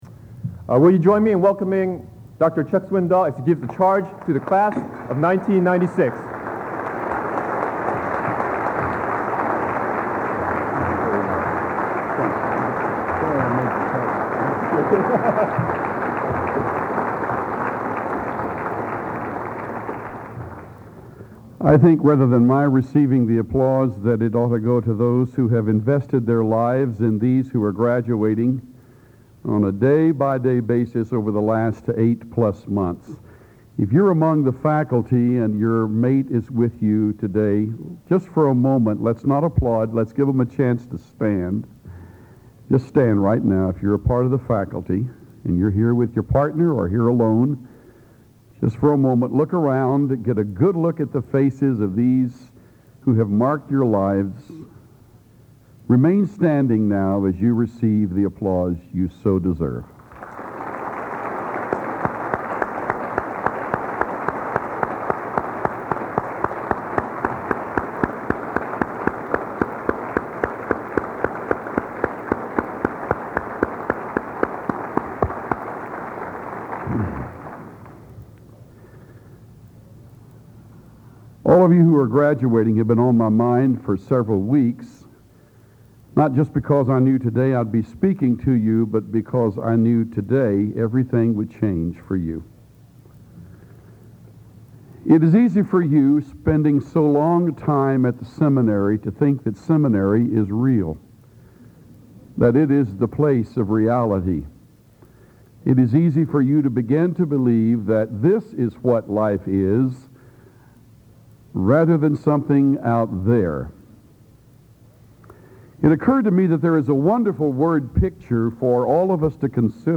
Charge to the Graduating Class of 1996